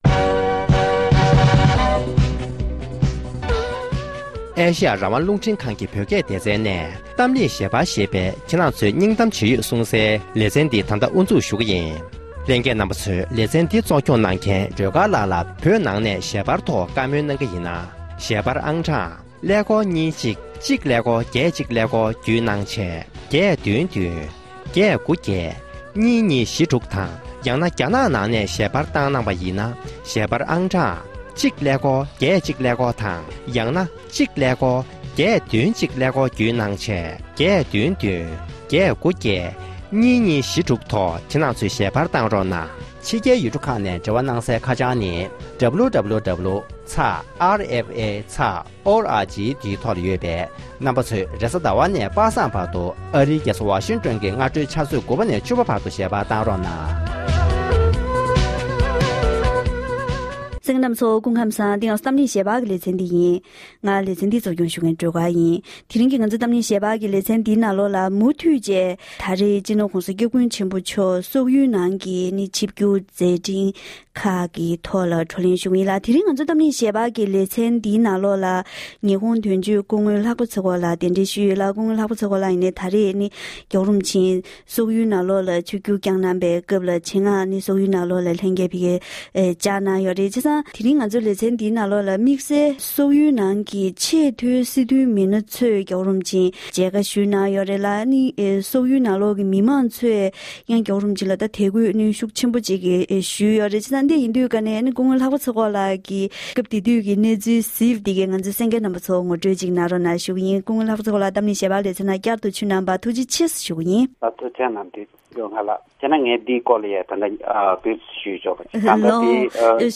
༄༅༎དེ་རིང་གི་གཏམ་གླེང་ཞལ་པར་གྱི་ལེ་ཚན་ནང་དུ་ཉི་ཧོང་དོན་གཅོད་དང་ལྷན་དུ་༸གོང་ས་མཆོག་སོག་ཡུལ་དུ་ཆིབས་བསྒྱུར་གནང་སྐབས་ཆེས་མཐོའི་དཔོན་རིགས་ཚོས་མཇལ་ཁ་ཞུས་ཡོད་པའི་སྐོར་དང་ཉི་ཧོང་ནང་ལ་ཡང་དཔོན་རིགས་ཁག་ཅིག་གིས་མཇལ་ཁ་ཞུས་ཡོད་པའི་ཐོག་གླེང་མོལ་གནང་བར་གསན་རོགས༎